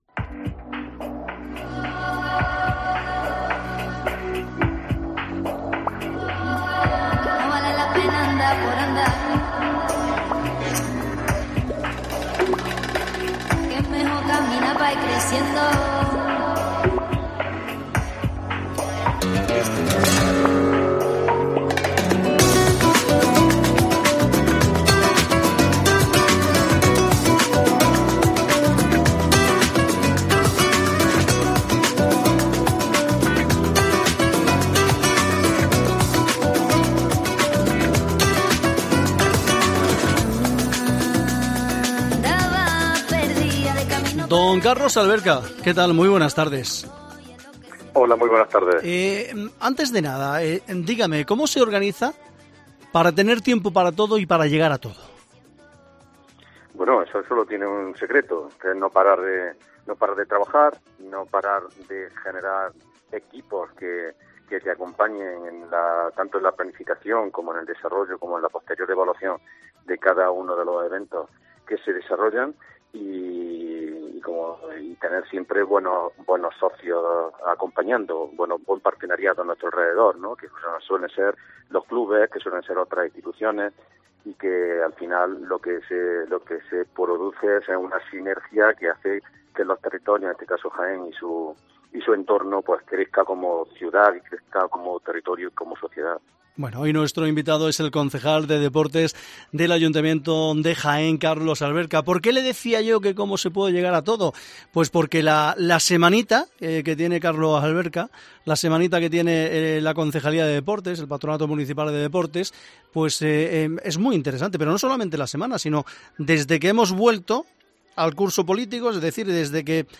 Hoy en COPE charlamos con Carlos Alberca, concejal de Deportes del ayuntamiento de Jaén